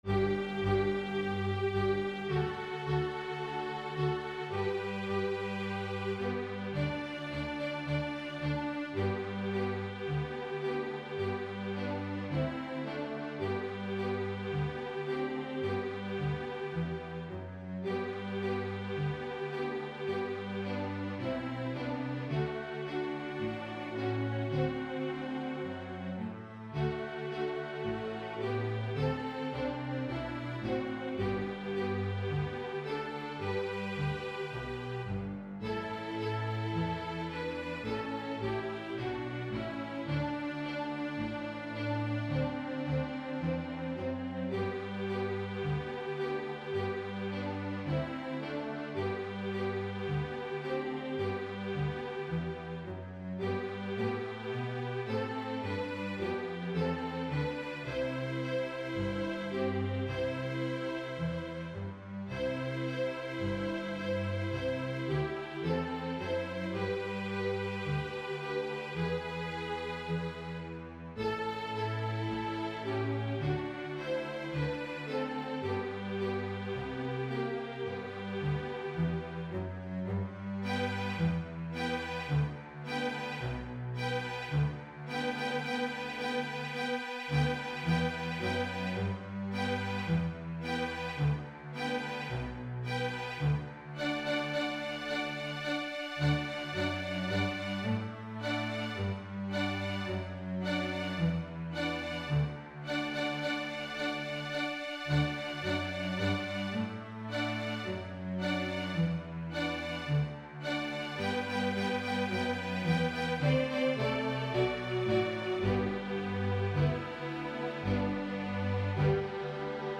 This is a unique composition in that at a slower tempo, it is suitable for a beginning string orchestra (first year) while at a faster tempo, it will sound great with an advanced group.
Both versions are on this mp3. The tempo markings on the music state FIRST YEAR STRINGS QUARTER = 120-132.....ADVANCED STRINGS HALF = 120 - 140.
FOLK MUSIC; BLUEGRASS MUSIC
fiddle-fun-m.mp3